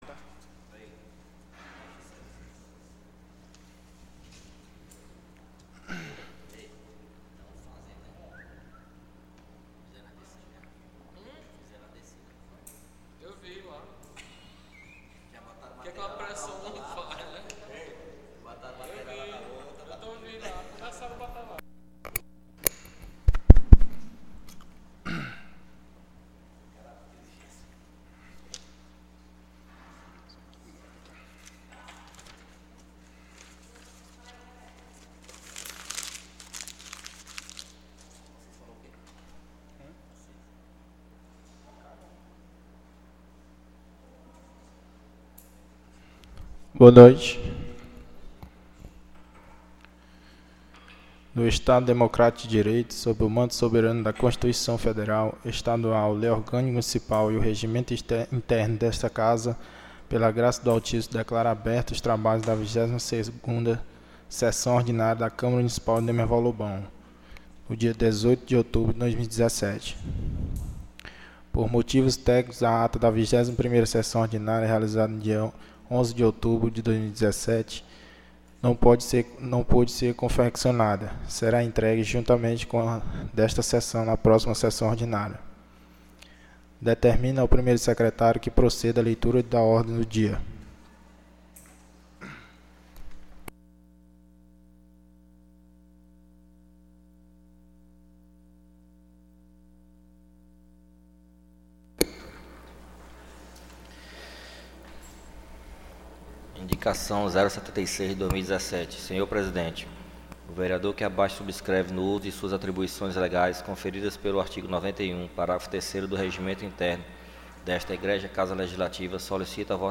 22ª SESSÃO ORDINÁRIA 18/10/2017